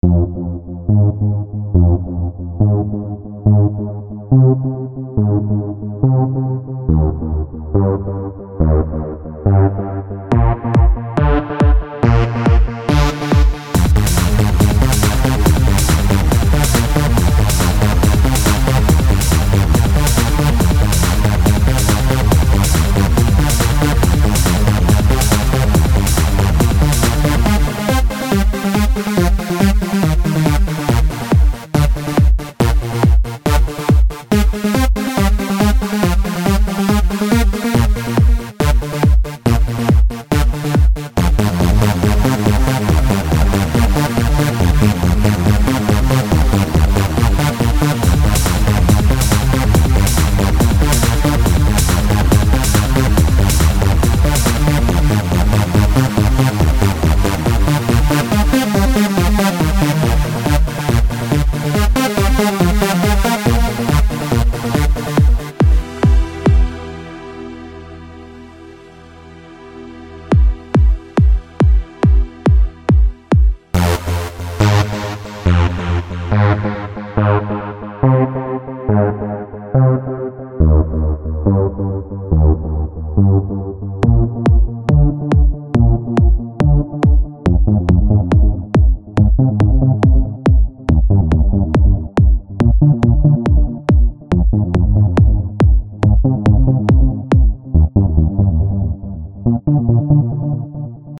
Maybe it's too silent? You'd excpect the music go louder after the beats in the beginning.